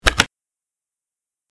btn_click.mp3